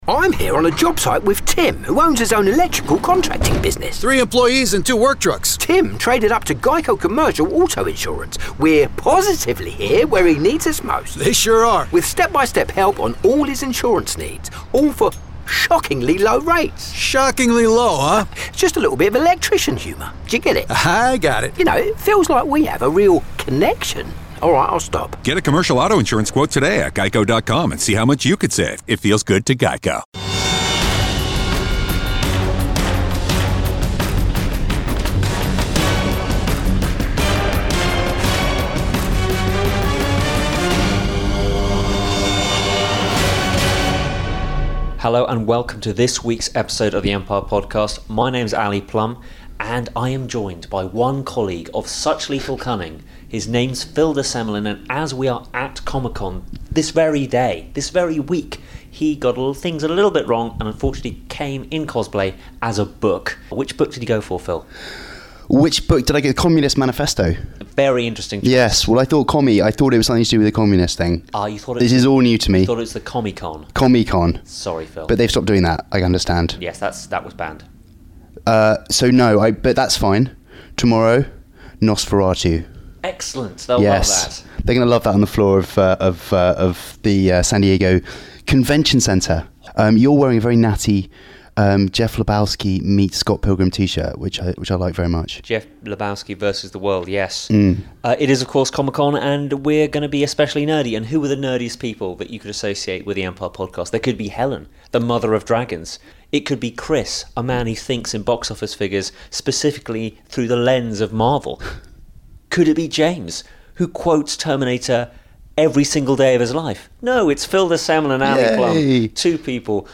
Expect to hear signs of severe sleep deprivation and some seriously lame jokes.